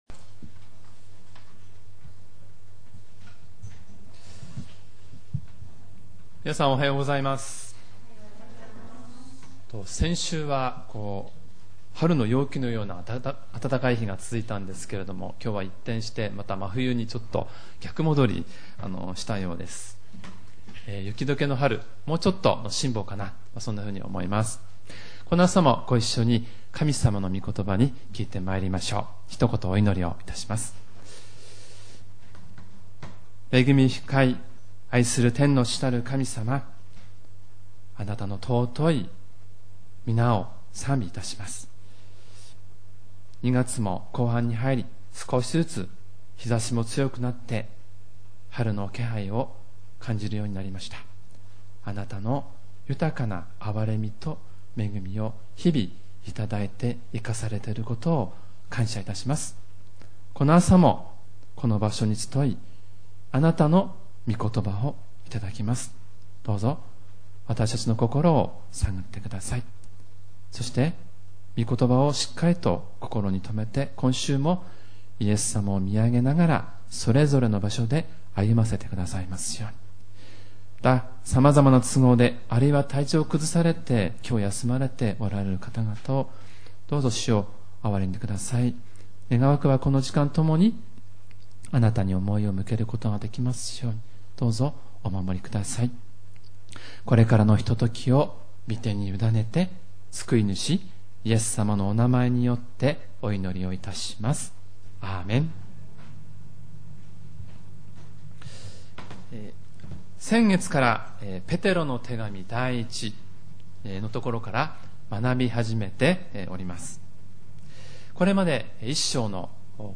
●主日礼拝メッセージ（MP３ファイル、赤文字をクリックするとメッセージが聞けます）